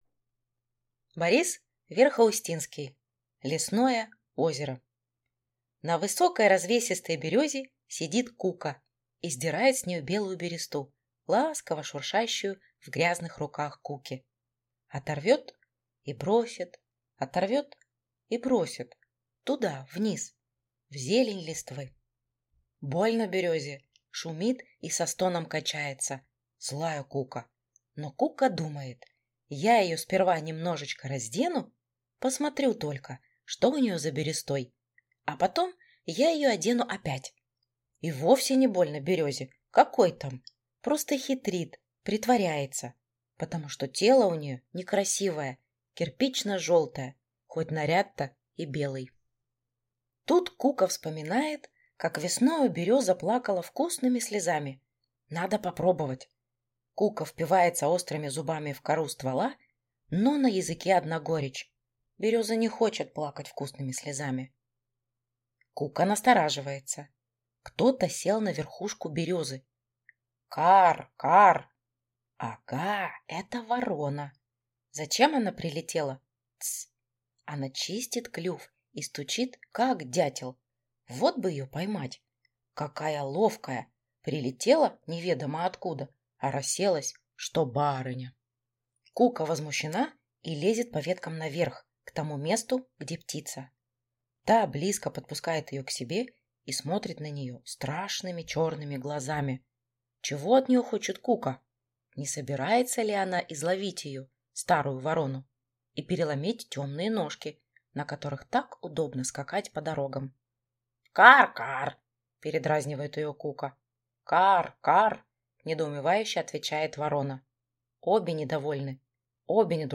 Аудиокнига Лесное озеро | Библиотека аудиокниг
Прослушать и бесплатно скачать фрагмент аудиокниги